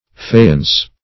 fayence - definition of fayence - synonyms, pronunciation, spelling from Free Dictionary Search Result for " fayence" : The Collaborative International Dictionary of English v.0.48: Fayence \Fa`y*ence"\ (f[aum]`[-e]*y[aum]Ns"), n. See Fa["i]ence .
fayence.mp3